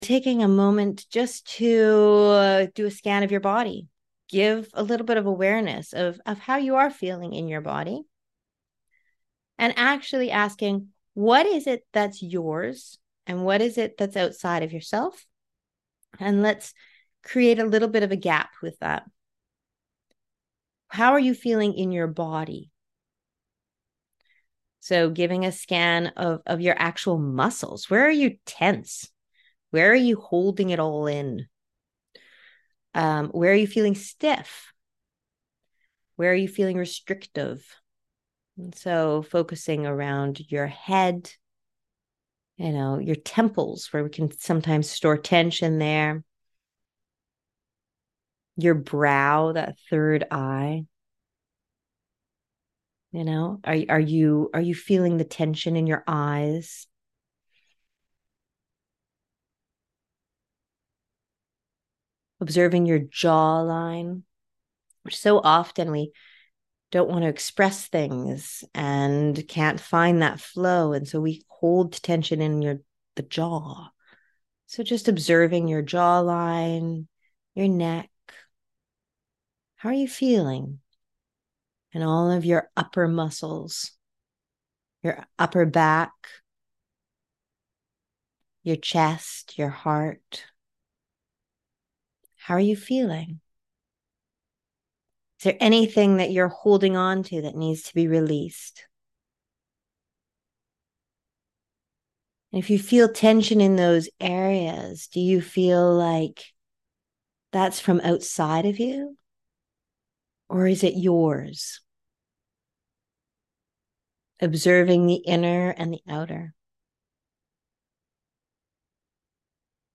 meditation-aligned-nights.mp3